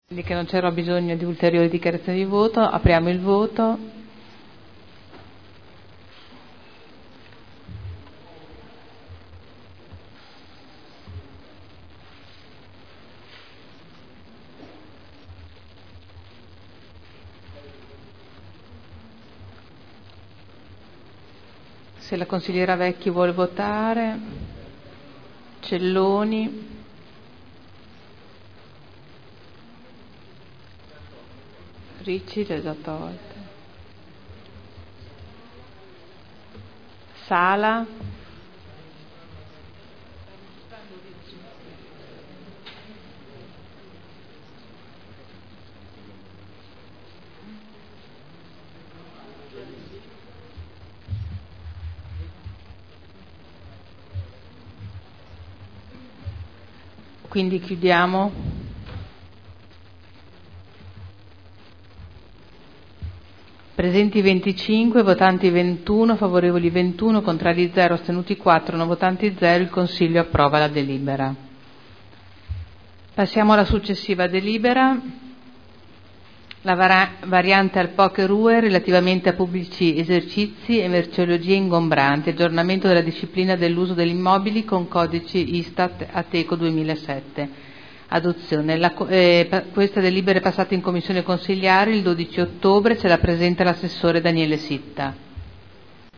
Seduta del 18 ottobre 2010 - Mette ai voti delibera: Strade vicinali di uso pubblico – Contributo per la manutenzione – Approvazione Piano-Programma e provvedimenti (Commissione consiliare del 5 ottobre 2010)